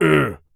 Male_Grunt_Hit_Neutral_05.wav